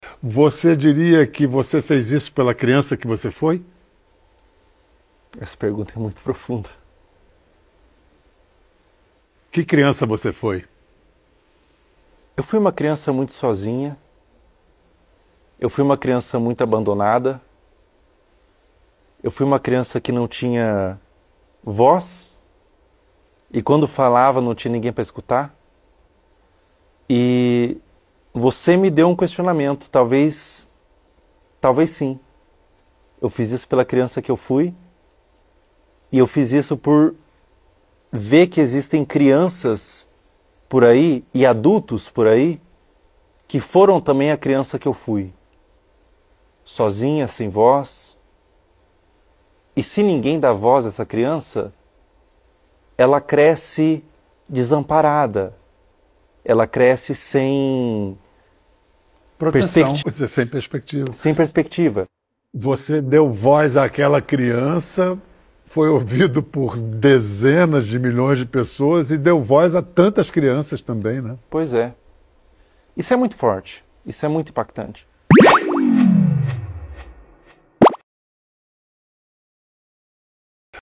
O youtuber Felca relembrou momentos marcantes de sua infância durante participação no Conversa com Bial e emocionou ao falar sobre solidão e abandono.